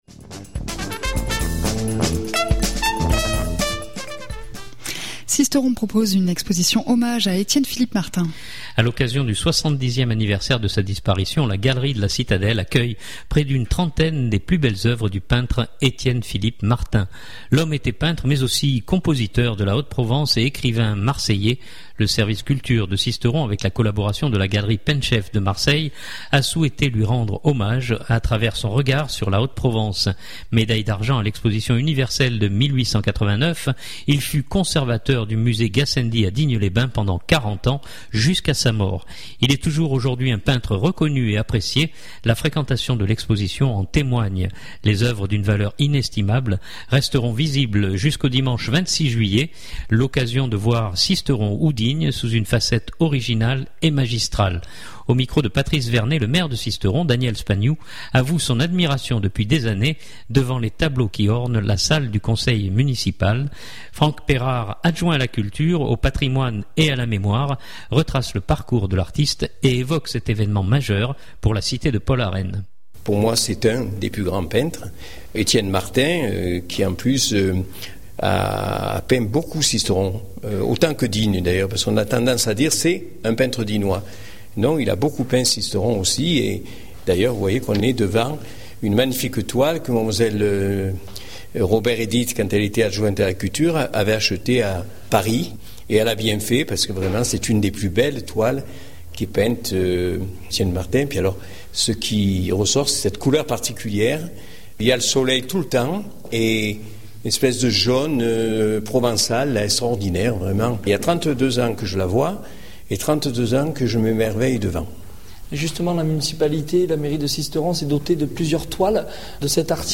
Franck Pérard, adjoint à la culture, au patrimoine et à la mémoire retrace le parcours de l’artiste et évoque cet évènement majeur pour la cité de Paul Arène.